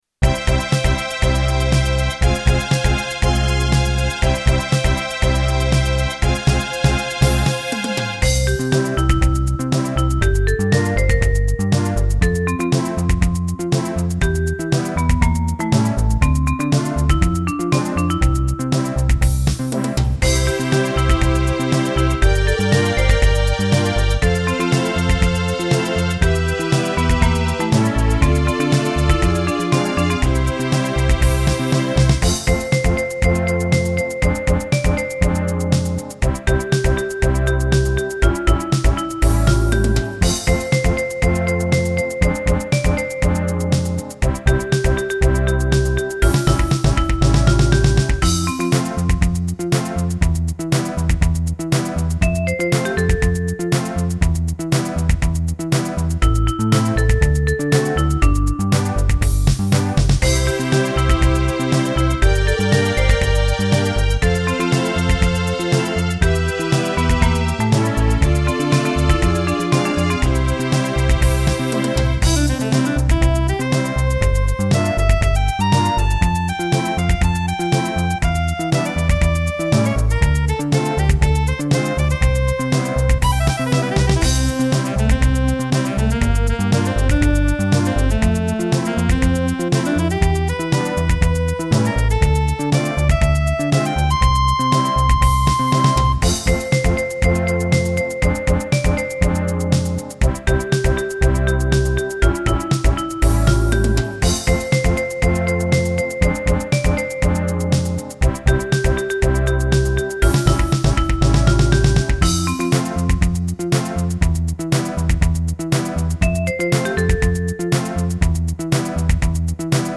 もともとは氏がＭＳＸ時代に作っていたデータ（ヤマハ オートアレンジャー）ですが、ドラムがなかったので私が打ち込み追加したものです。 MIDI環境再設定のテストを兼ねて、ヤマハの古いソフト音源 S-YXGで演奏→録音。 同じデータなのに音が全然イイ・・・。